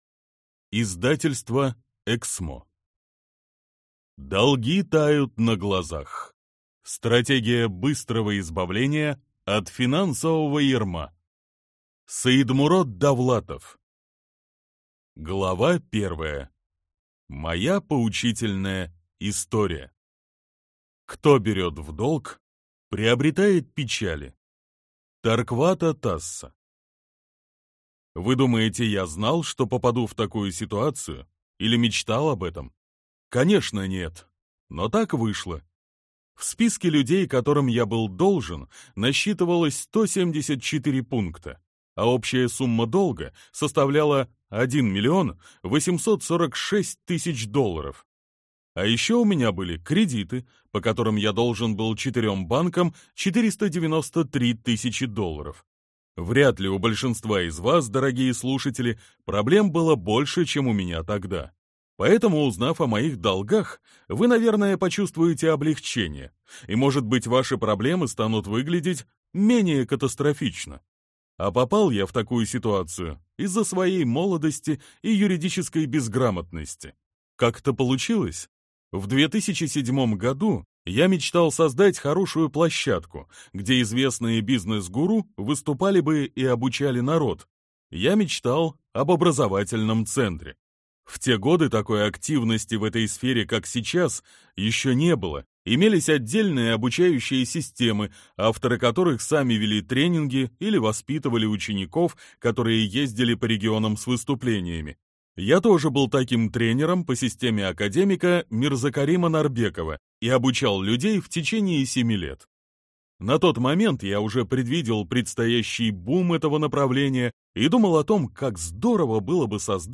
Аудиокнига Долги тают на глазах | Библиотека аудиокниг
Прослушать и бесплатно скачать фрагмент аудиокниги